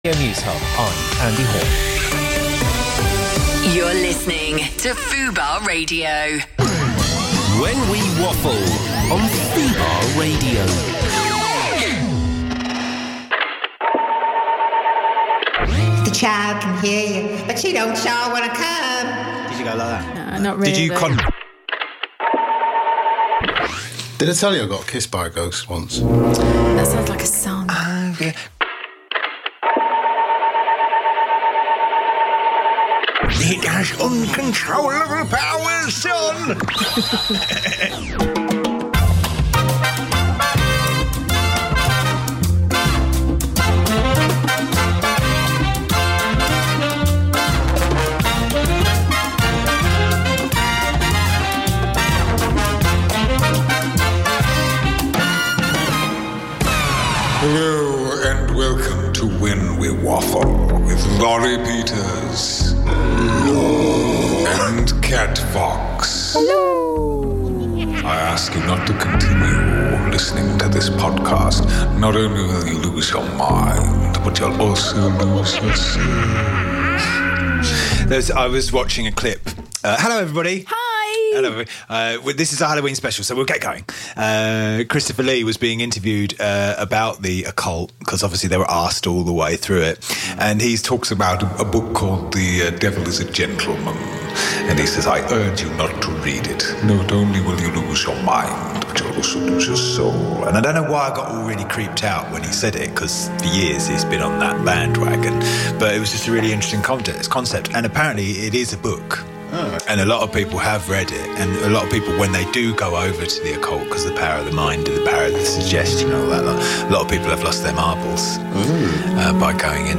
The Mimic Terry Mynott mocks beloved and less beloved stars whilst bantering with his co-hosts